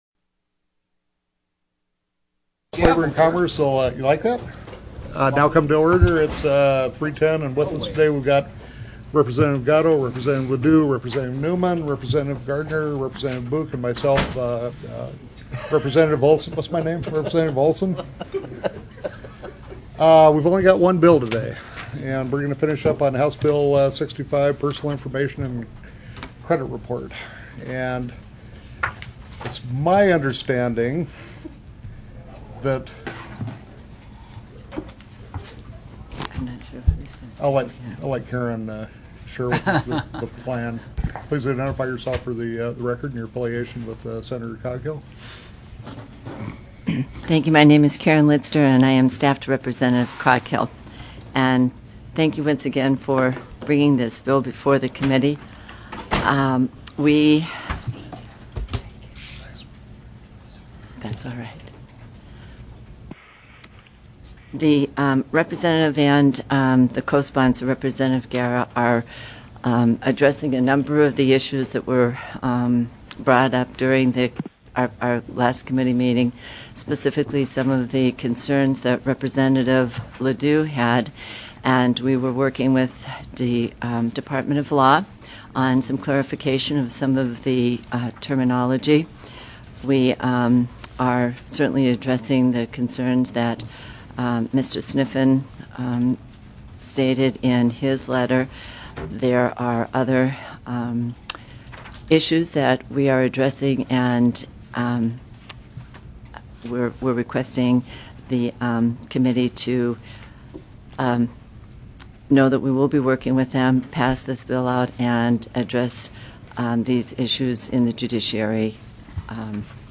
+= HB 65 PERSONAL INFORMATION & CONSUMER CREDIT TELECONFERENCED